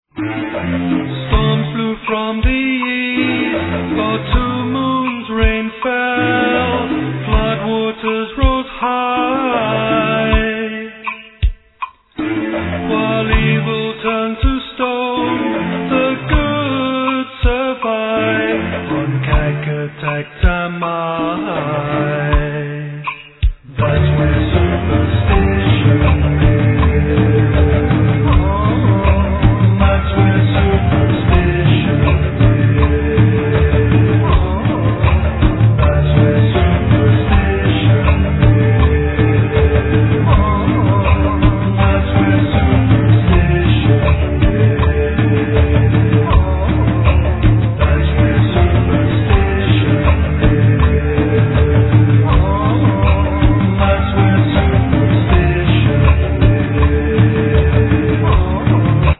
Recorder
Oboe d'amore
Piano, Guitar
Voice, Violin